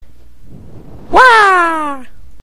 Play, download and share UAAAH original sound button!!!!
uaah.mp3